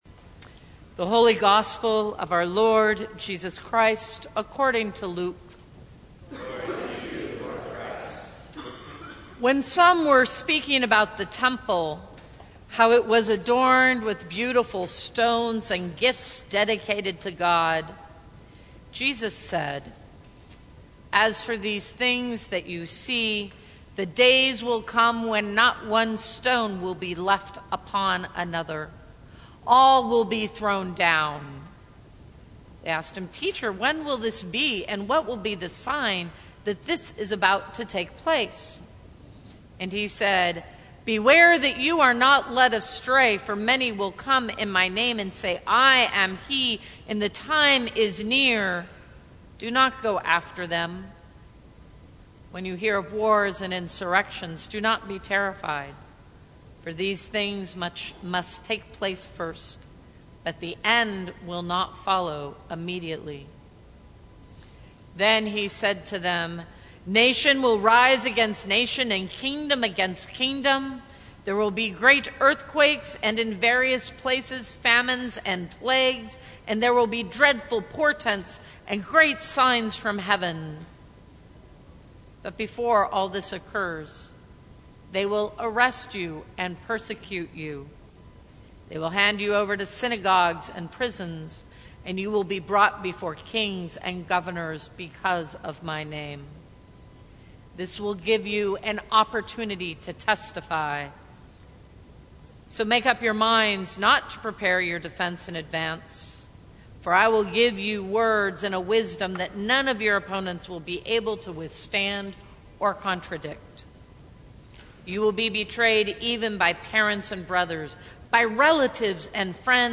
Sermons from St. Cross Episcopal Church Another Way Nov 21 2019 | 00:13:51 1x 00:00 / 00:13:51 Subscribe Share Apple Podcasts Spotify Overcast RSS Feed Share Link Embed